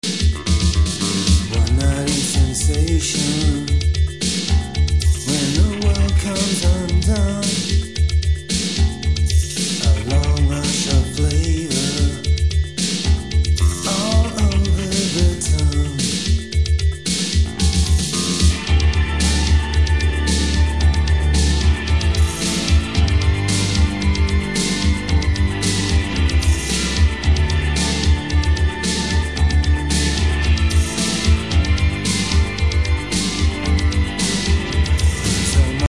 Recorded at La Maison and Trackdown studios.